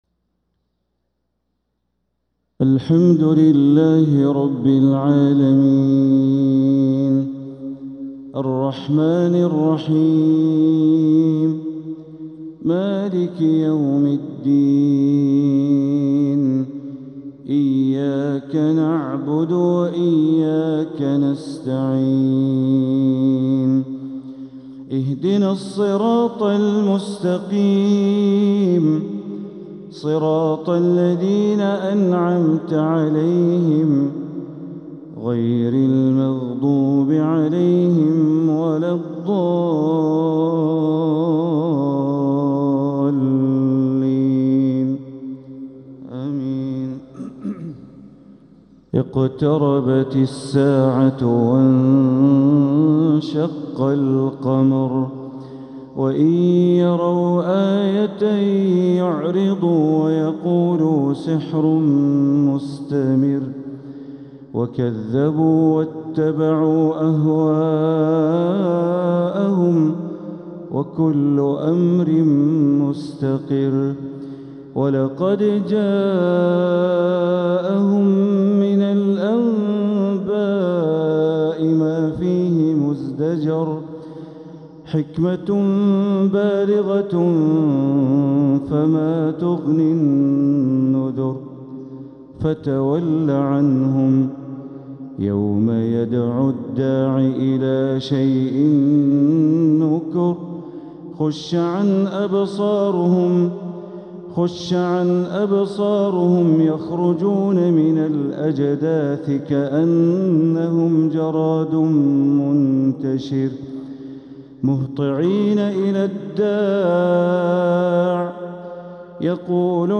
تلاوة لسورة القمر كاملة | فجر الإثنين 9-7-1447هـ > 1447هـ > الفروض - تلاوات بندر بليلة